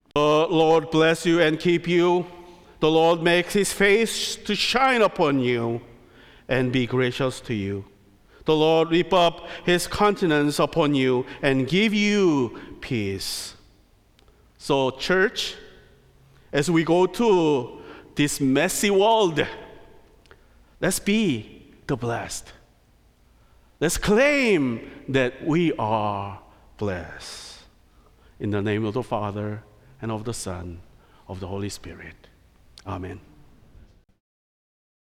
Service of Worship
Benediction